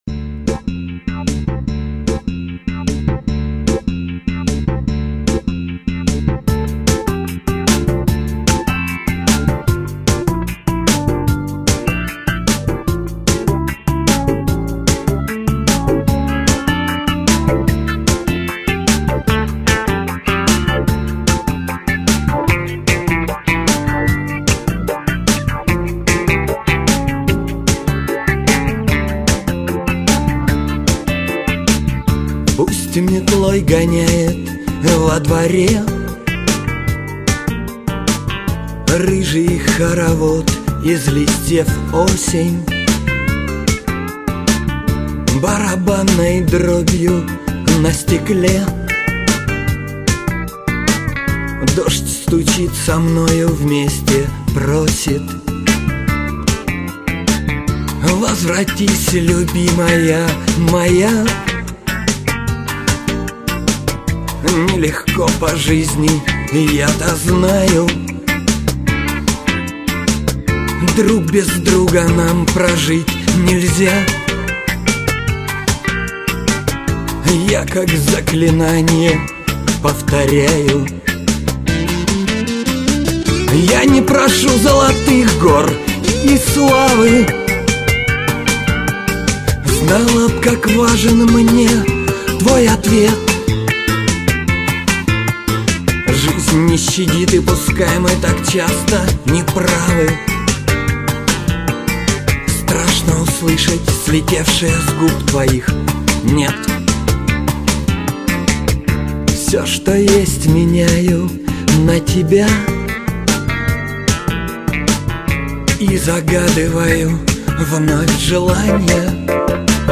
Вообще попса голимая...
Побольше бы вокальной выразительности!